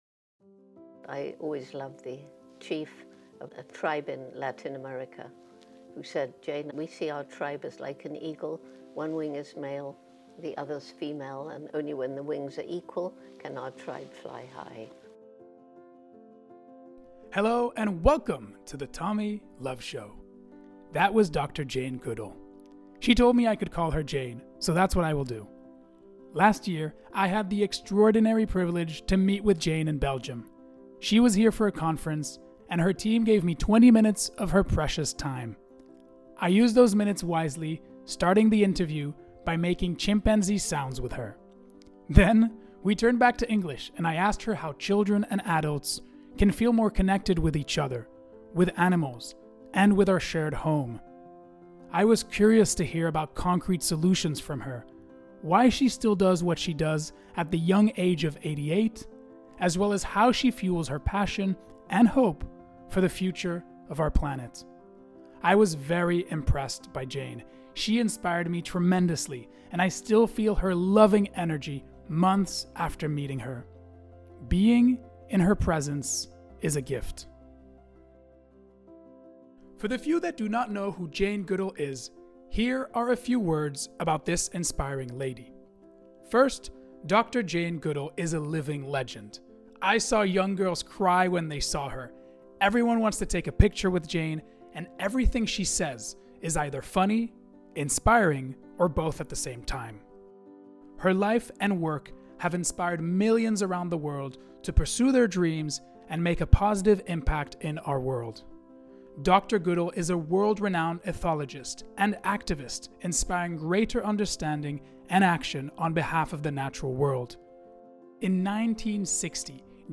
She was here for a conference and her team gave me 20 minutes of her precious time. I used those minutes wisely starting the interview by making chimpanzee sounds with her. Then we turned back to English and I asked her how children and adults can feel more connected with each other, with animals, and with our shared home.